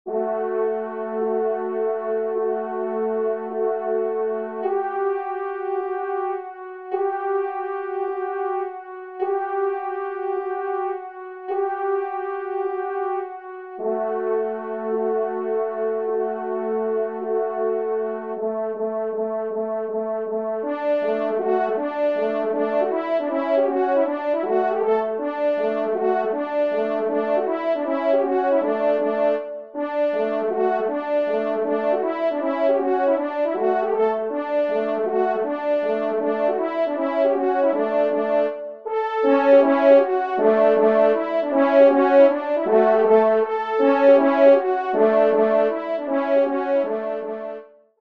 Danses polonaises
3e Trompe